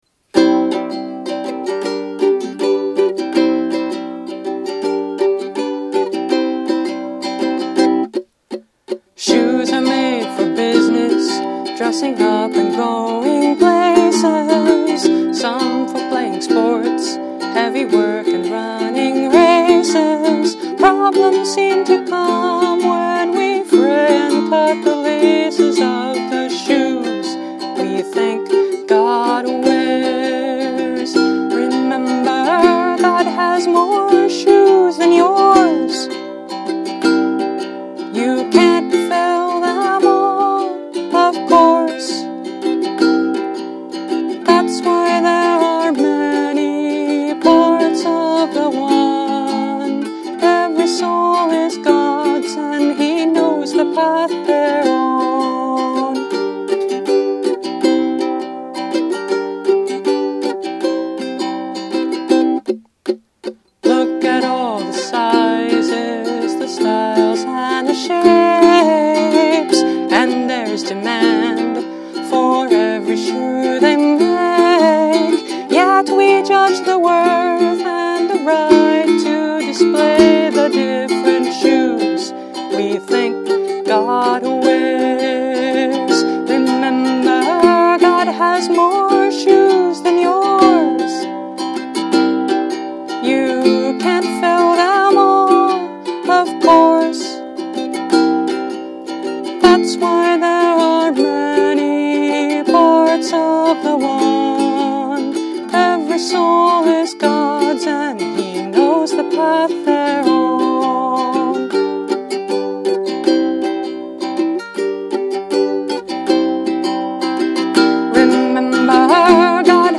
Instrument: Eventide – Mainland Mahogany Concert Ukulele